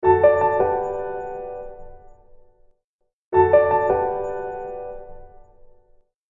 interphone.mp3